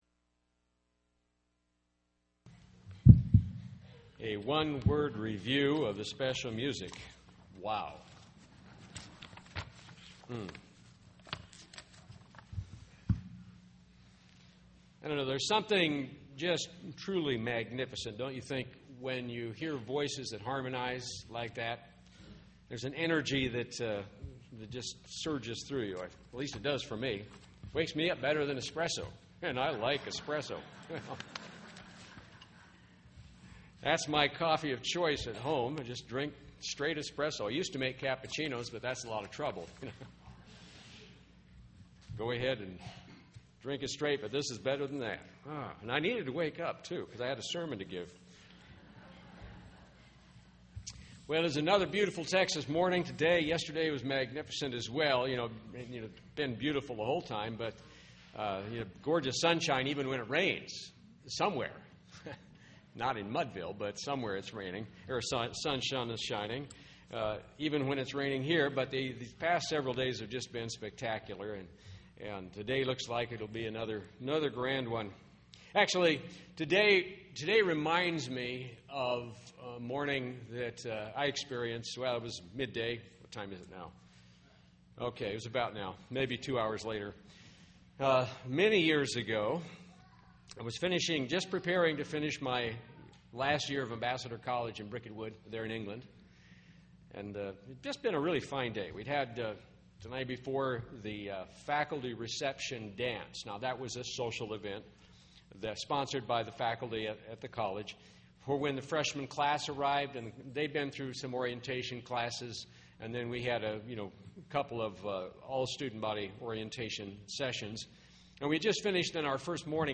This sermon was given at the Kerrville, Texas 2010 Feast site.